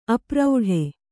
♪ apraudhe